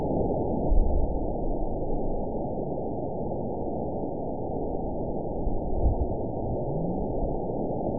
event 919934 date 01/28/24 time 22:31:11 GMT (1 year, 10 months ago) score 9.62 location TSS-AB04 detected by nrw target species NRW annotations +NRW Spectrogram: Frequency (kHz) vs. Time (s) audio not available .wav